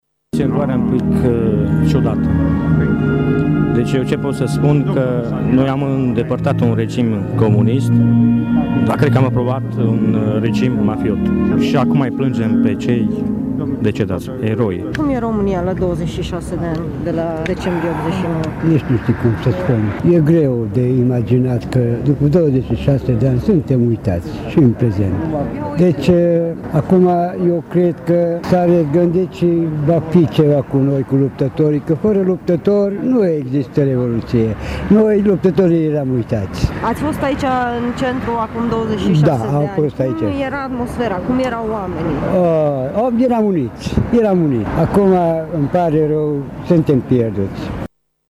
Ceremonia a avut loc, în această dimineaţă, la troiţa din Piaţa Victoriei.
Revoluţionarii prezenţi au spus că se simt uitaţi iar cei care au venit la putere după 1989 nu reprezintă idealurile Revoluţiei: